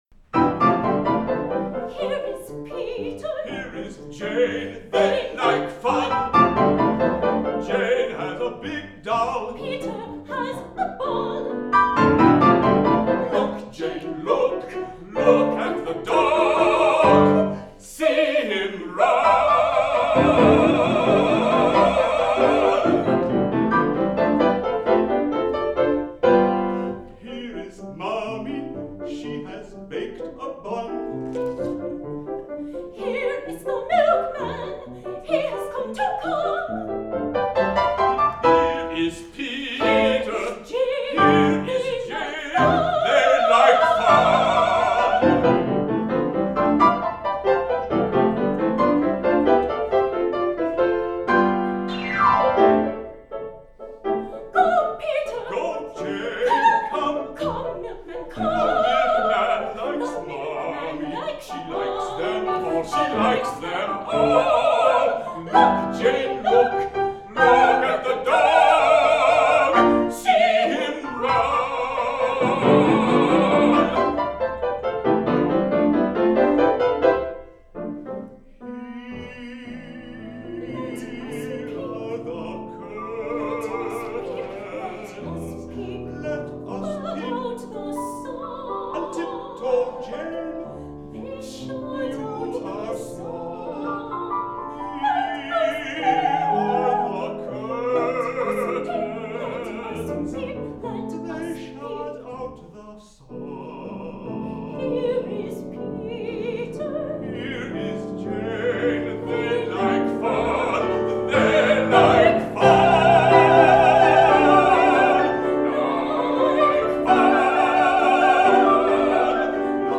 Duo song cycle for soprano, baritone and piano